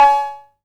Dirty South Cowbell.wav